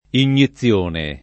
[ in’n’i ZZL1 ne ]